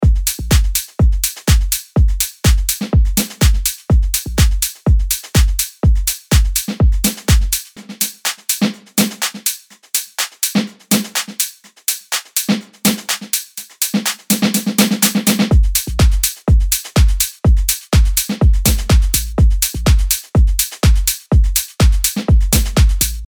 ダンスフロアに向けたパンチの効いた4つ打ちビートとグルーヴ
フロアを熱狂させる4つ打ちのキック、パンチのあるクラップ、シンコペーションの効いたハイハット、温かみを持つ質感のパーカッションで、あなたのトラックを躍動させましょう。
XO Expansion House プリセットデモ
7am_dance[461].mp3